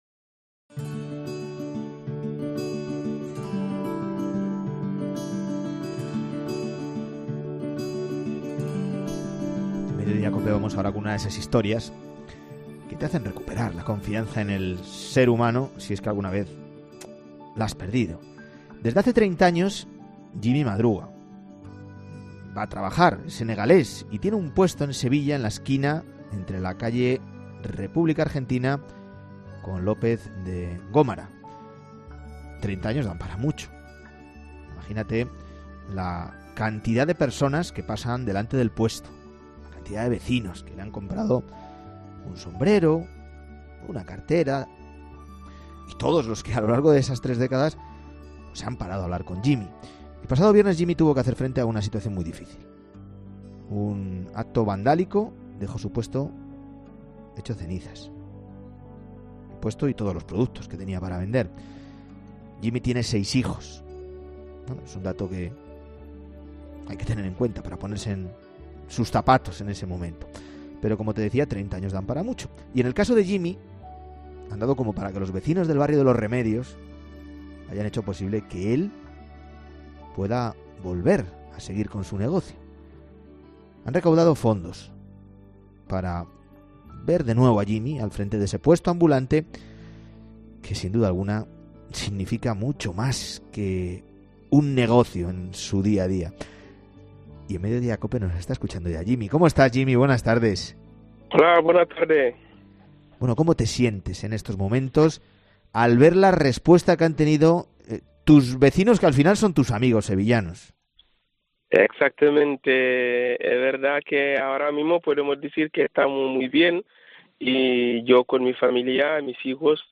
Han conversado los dos.